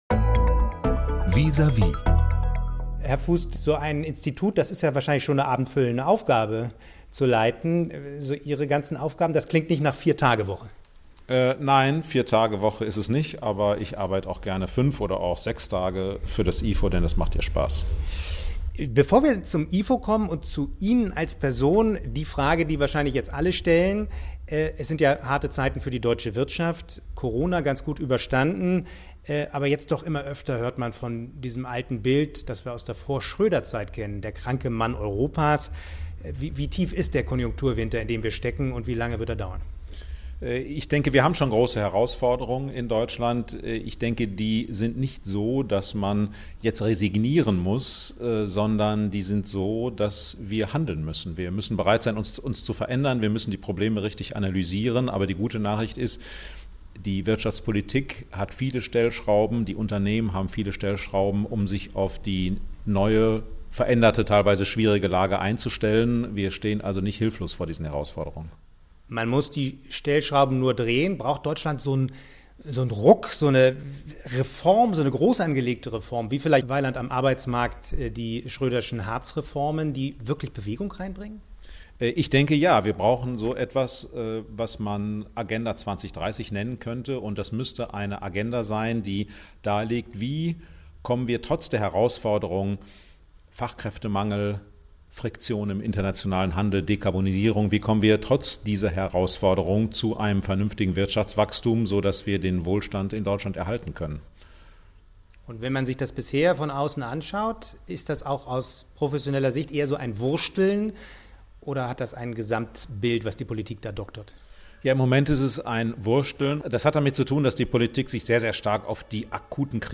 Clemens Fuest gehört zu den einflussreichsten Ökonomen Deutschlands.